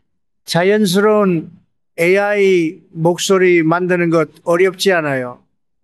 TTS로 만들어 보겠습니다.
AI 목소리는 도날드 트럼프 목소리입니다.
TTS(Text-to-Speech) 서비스를 통해 만든 목소리는 어딘가 로봇같이 어색함이 남아 있는 것을 확인할 수 있어요.